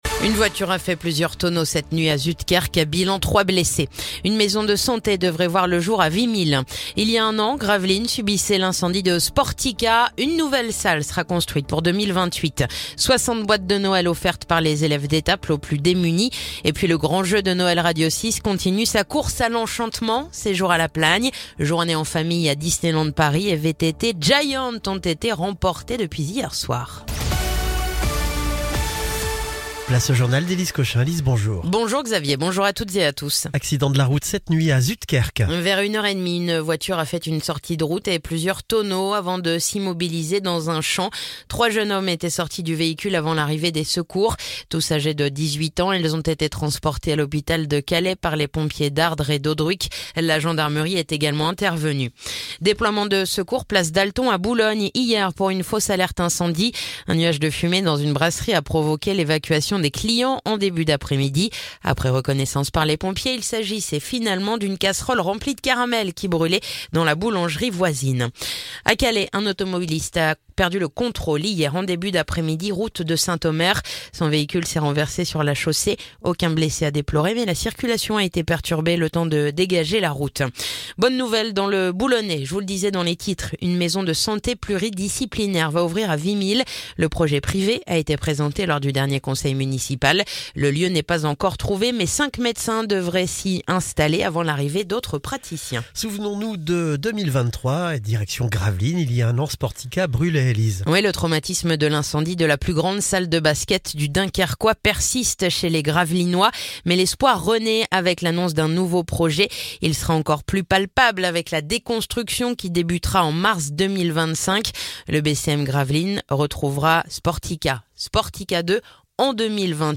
Le journal du mardi 24 décembre